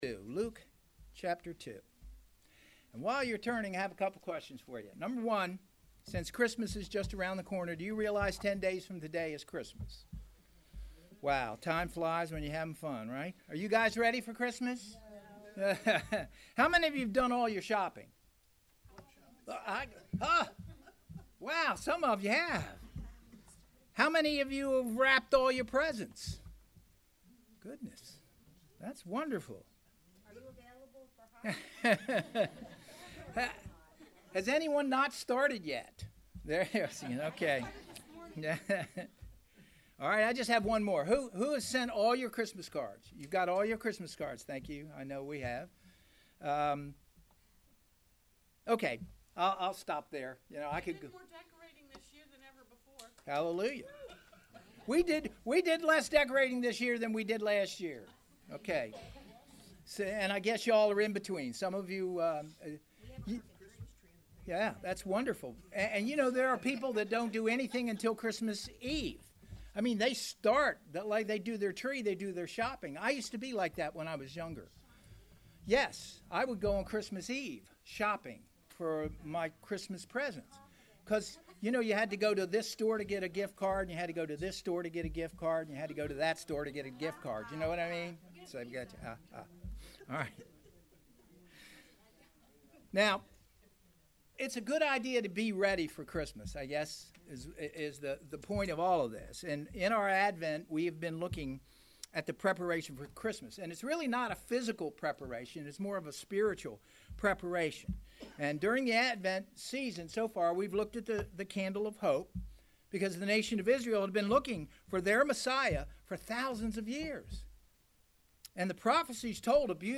Series: Characters of Christmas Service Type: Sunday Morning Worship